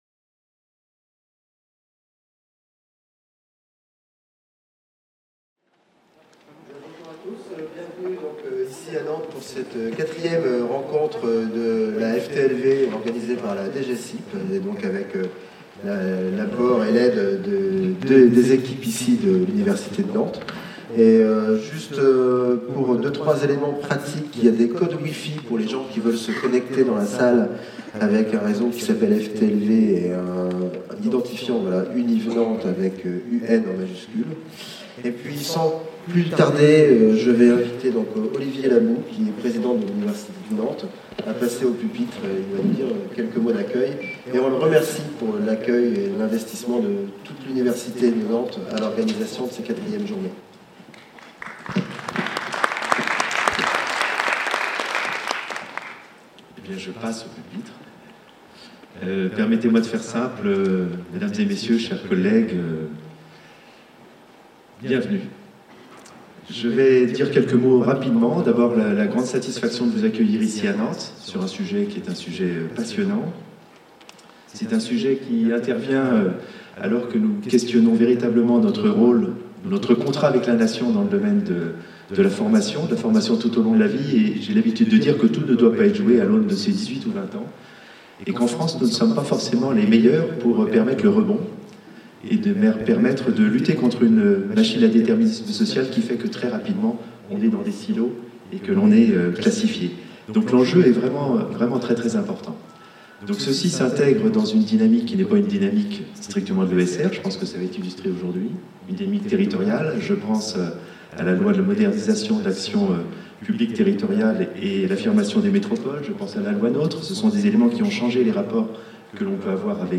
4ème rencontre : coorganisée le 5 décembre 2017, par la DGESIP et l'université de Nantes au Stéréolux (Ile de Nantes). Cycle de journées de rencontres sur les nouveaux modèles pour la F.T.L.V. 1er modèle : les effets du numérique sur l'organisation du travail, les réponses de l'enseignement supérieu. présentation : Ouverture de la Journée - Introduction Olivier LABOUX (président de l'Université de Nantes.)